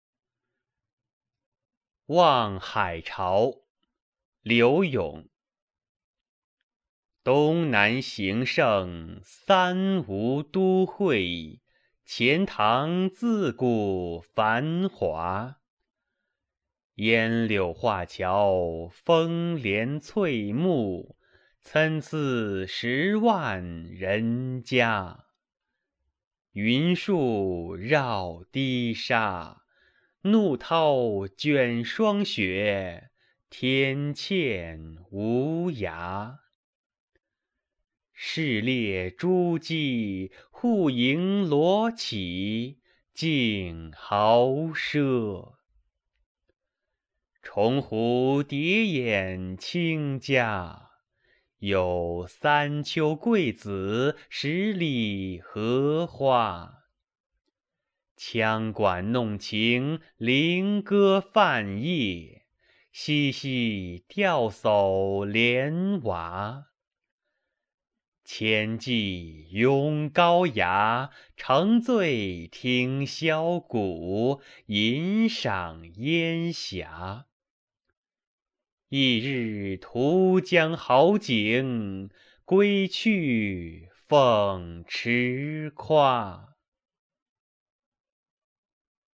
柳永《望海潮》原文和译文（含赏析、朗读）